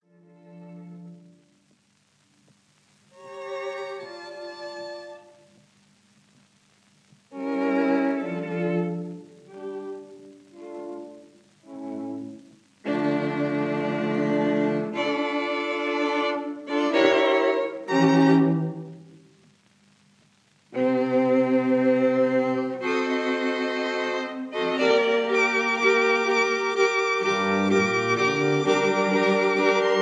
violins
viola
cello
Introduzione in D minor — Maestoso ed Adagio